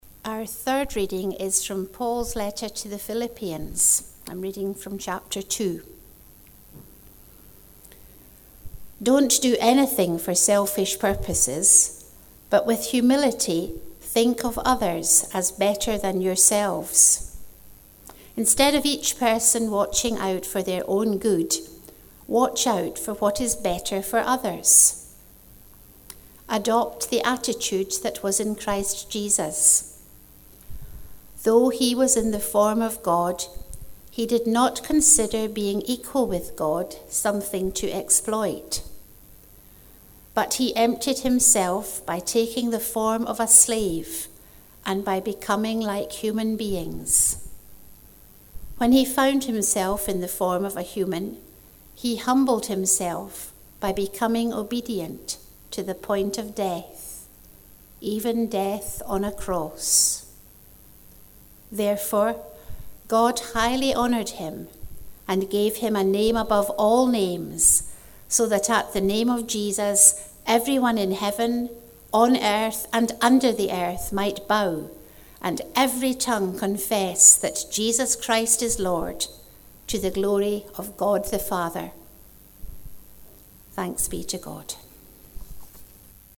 Christmas Eve at St. Mungo's - 24 December 2018
Watchnight Service and the celebration of Jesus birth
reading from Philippians